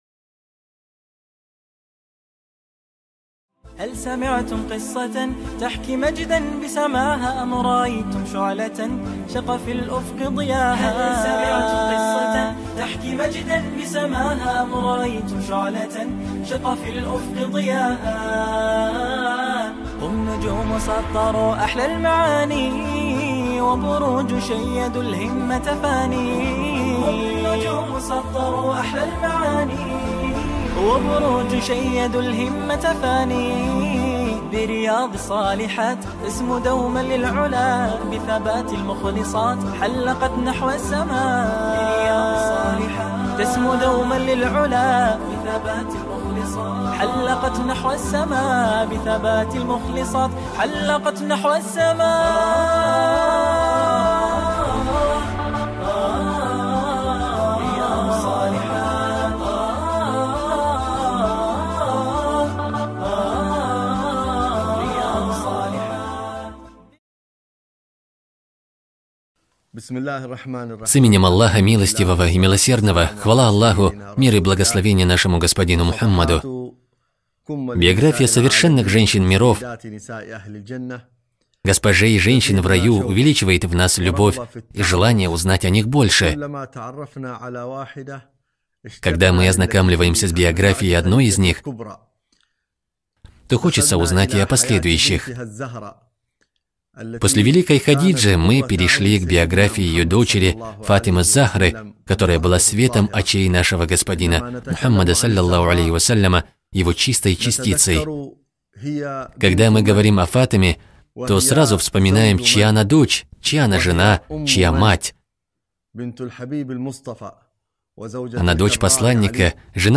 Цикл лекций «Совершенные женщины»